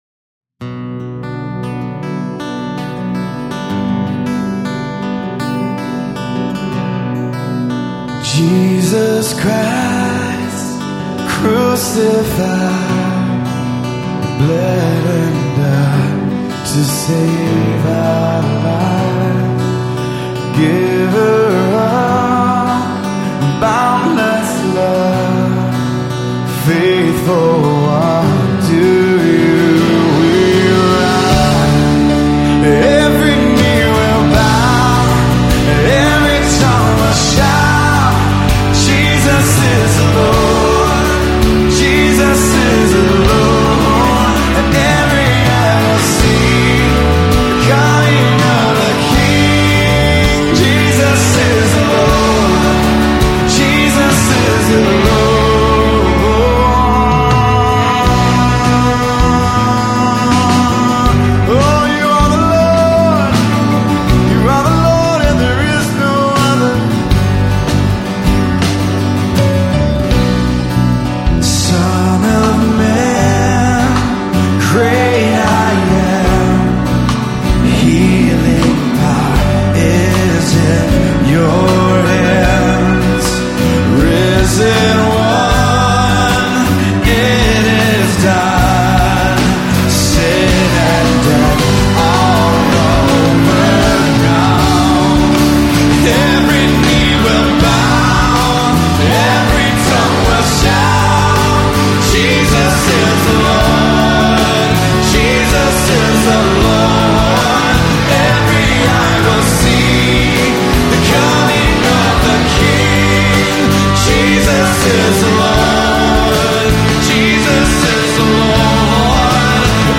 Pin It Like a Lion - Gm Say Say - C Our God - G#m Jesus is the Lord - Bm O taste and see - G I just noticed we have more minor songs than major.